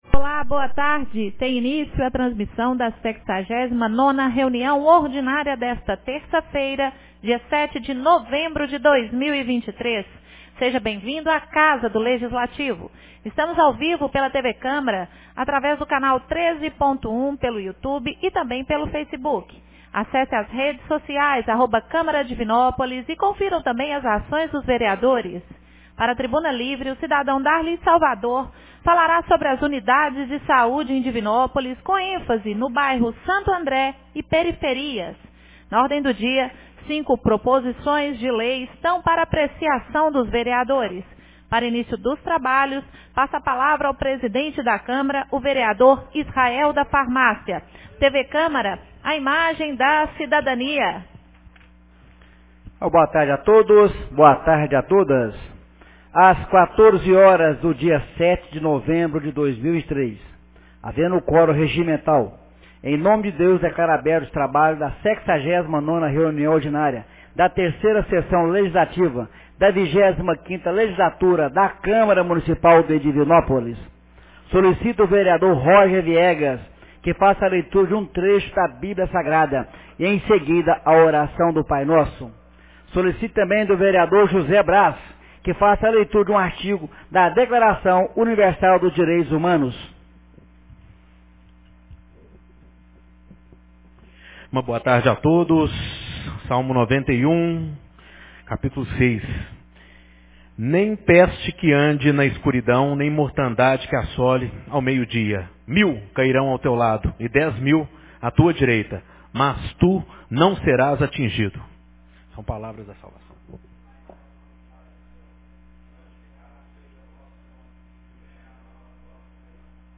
69ª Reunião Ordinária 07 de novembro de 2023